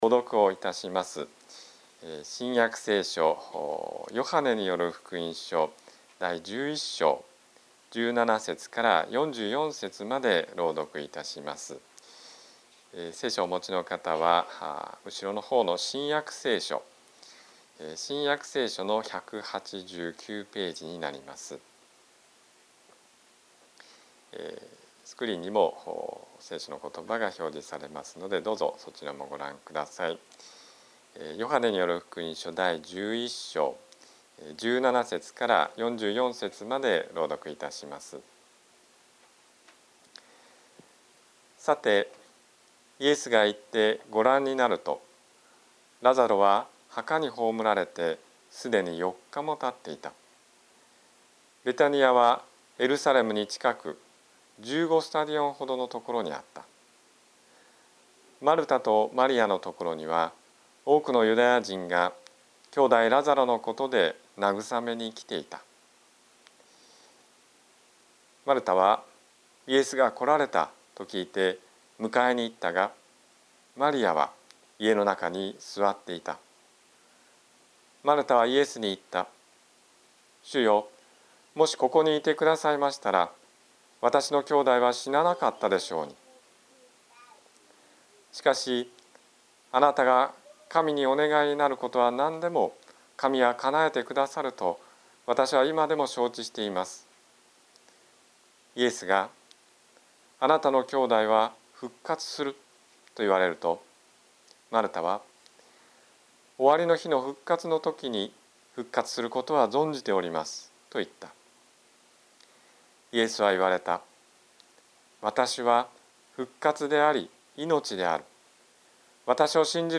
宝塚の教会。説教アーカイブ。
日曜 朝の礼拝